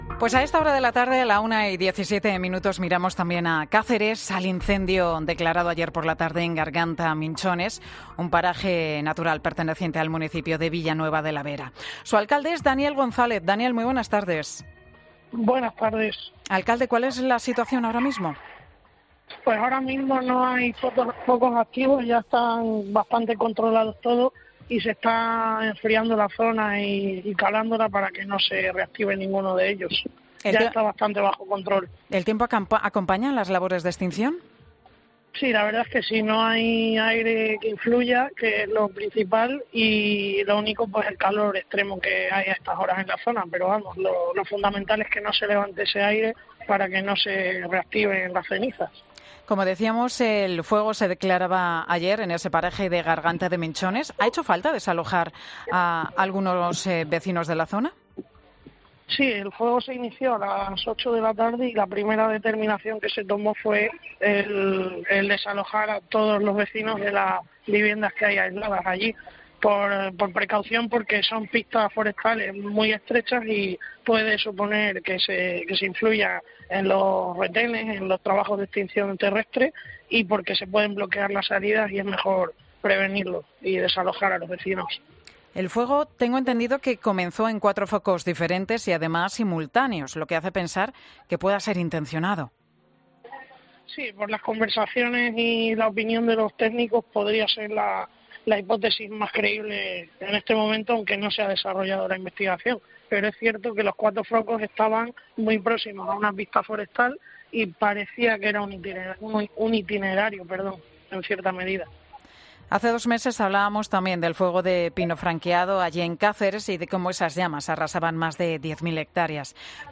El alcalde de este municipio ha pasado por los micrófonos de 'Mediodía COPE' para actualizar toda la información.